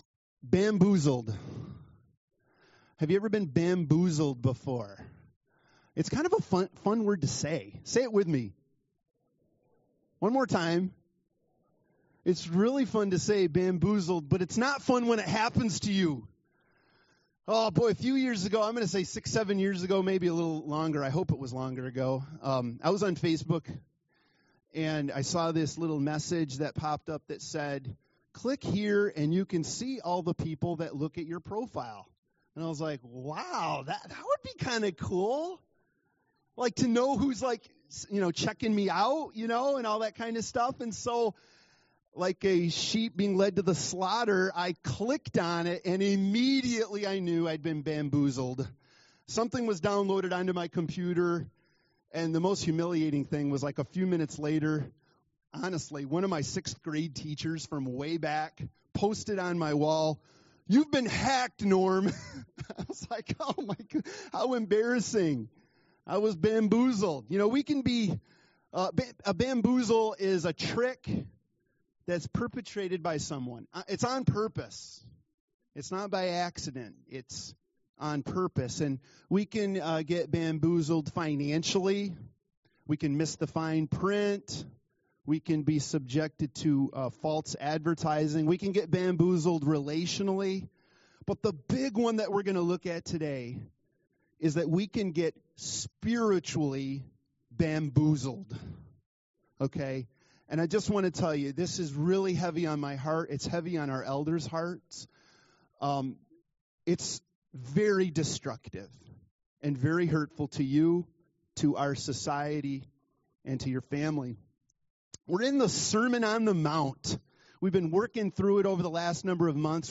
Learning to Be Discerning Service Type: Sunday Morning « What Path Am I On?